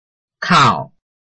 拼音查詢：【饒平腔】kau ~請點選不同聲調拼音聽聽看!(例字漢字部分屬參考性質)